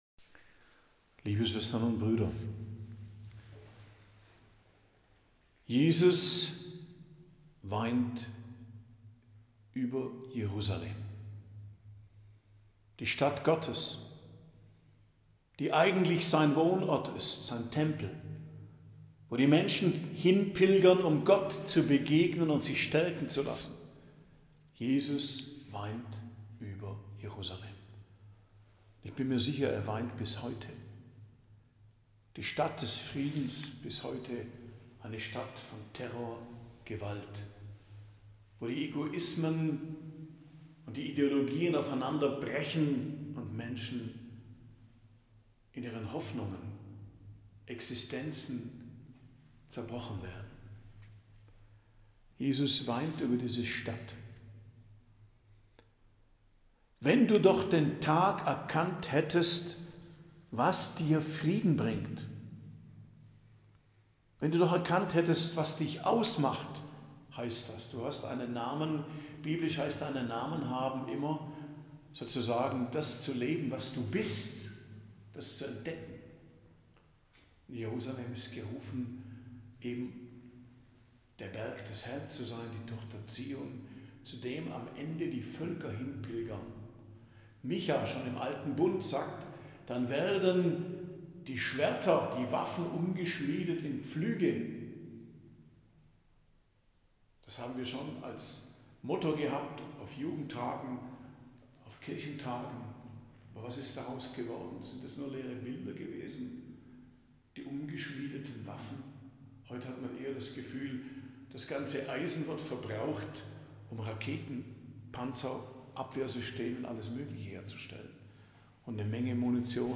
Predigt am Donnerstag der 33. Woche i.J. 21.11.2024 ~ Geistliches Zentrum Kloster Heiligkreuztal Podcast